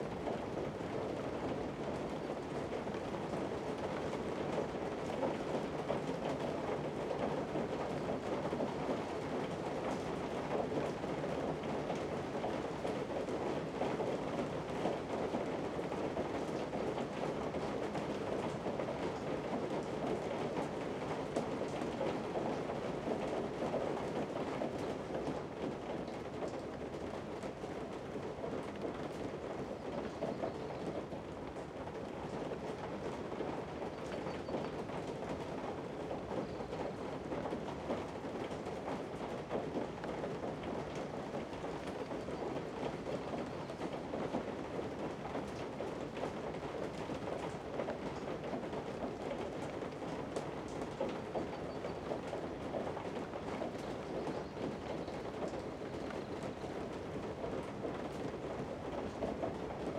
BGS Loops / Interior Day / Inside Day Rain.ogg
Inside Day Rain.ogg